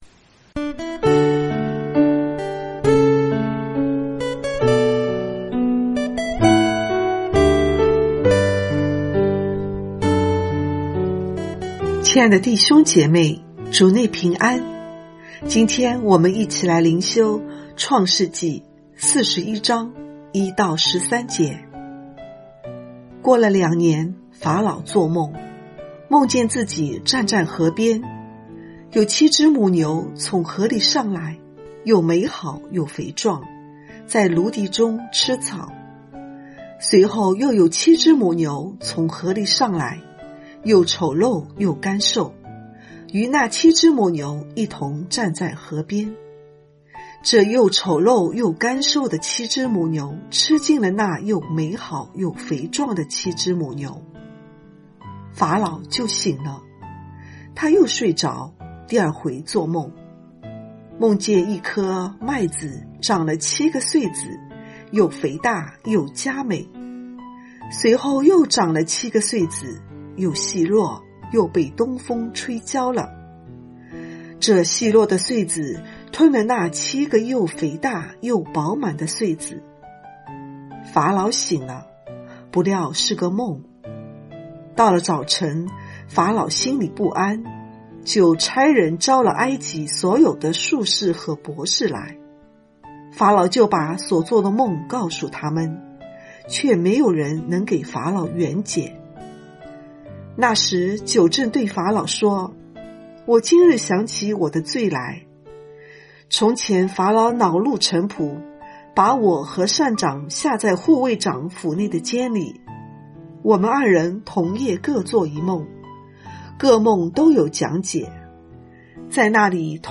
這是把通讀整卷創世記和每天靈修結合起來的一個計劃。每天閱讀一段經文，聆聽牧者的靈修分享，您自己也思考和默想，神藉著今天的經文對我說什麼，並且用禱告來回應當天的經文和信息。